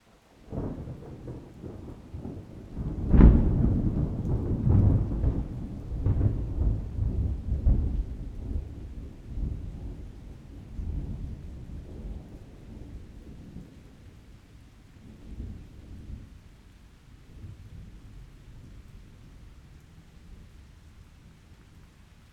thunder-2.mp3